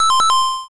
Pause.wav